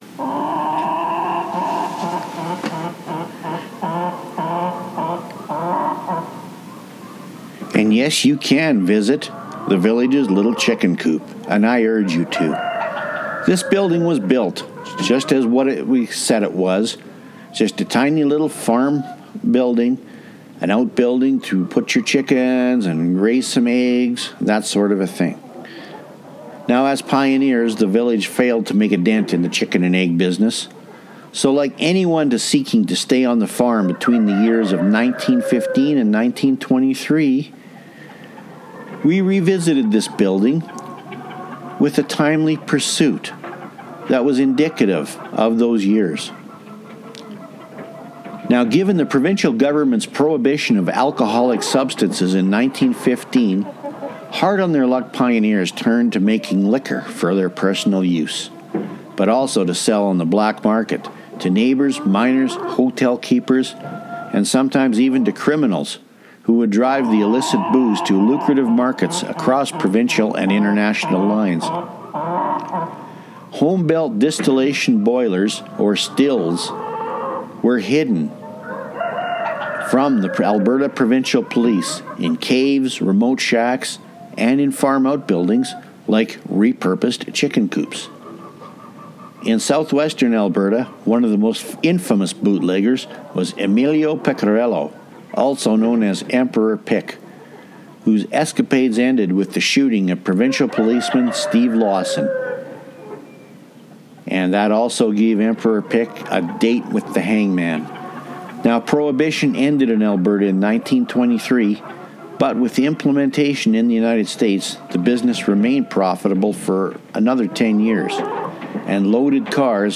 Audio Tour